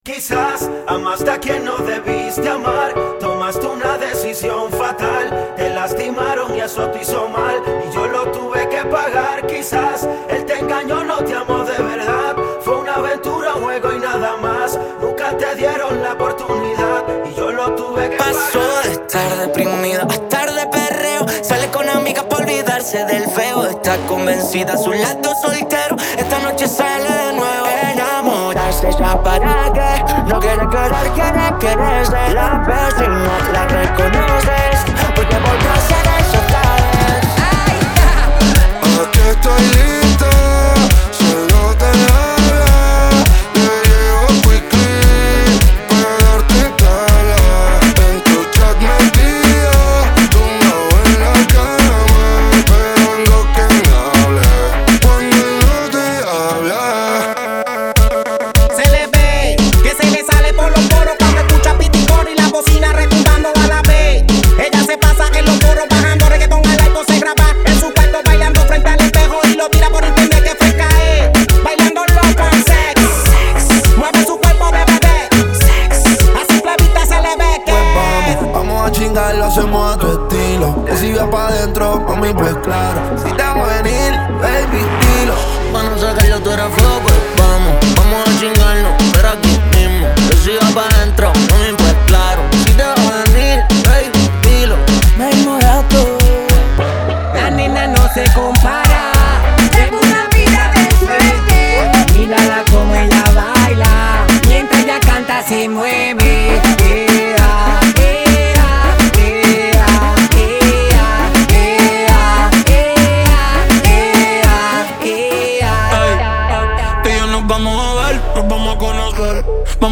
Acapella